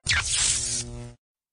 vv_electricity
electricity.mp3